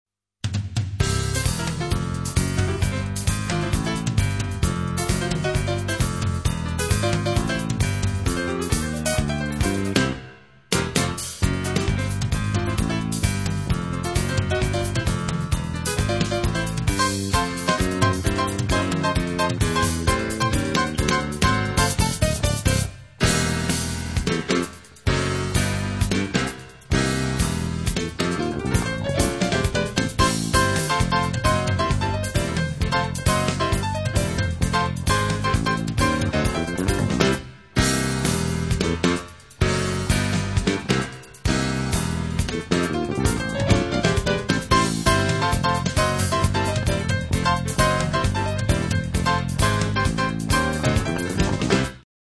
Piano
Trompette
Saxophone alto, Saxophone tenor
Basse
Batterie
Percussion